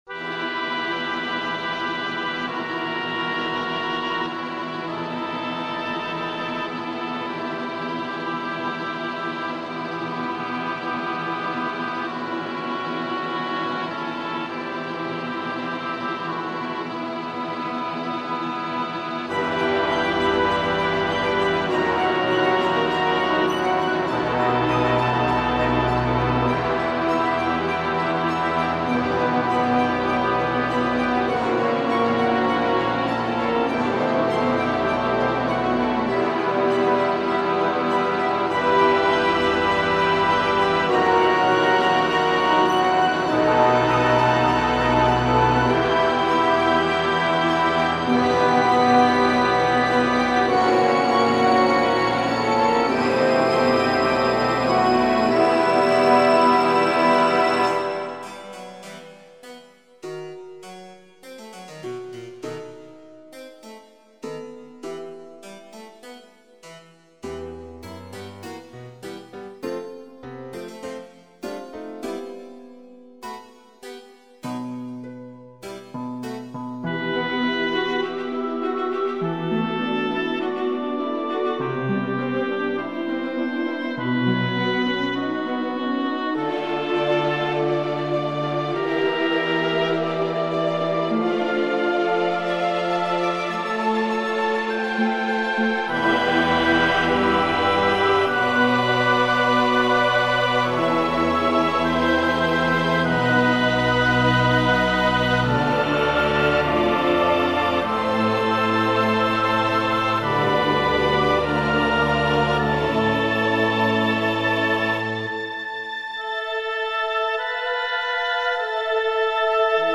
:D Je ne m'attendais à entendre du clavecin dans cette nocta, mais ça marche plutôt bien.
Sinon, le son est assez sale (gros soucis d'orchestration et de mix), les harmonies un peu scolaires (idem que 3.). Je ne comprends pas le pourquoi de cette fin ; ça part dans tous les sens.